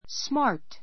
smart A1 smɑ́ː r t ス マ ー ト 形容詞 ❶ 利口な , 頭の回転が速い （bright） a smart boy [dog] a smart boy [dog] 利口な少年[犬] Ed is smart in math.